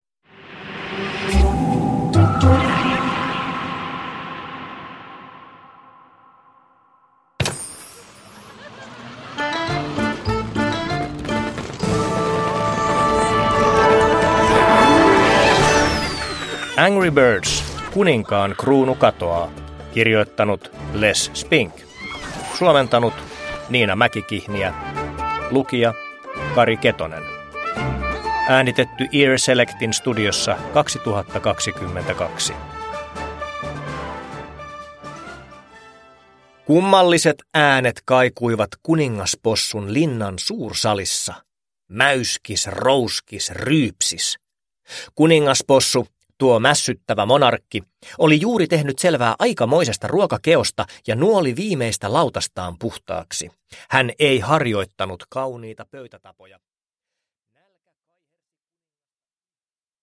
Angry Birds: Kuninkaan kruunu katoaa – Ljudbok – Laddas ner